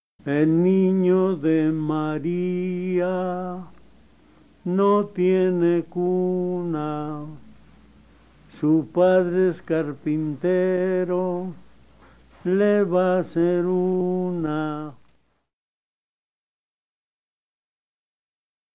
Keywords: música de navidad
Grabaciones de campo
Canto de Las pastoras, tercera alabanza (5 estrofas) (Guerrero); Villancicos: 6.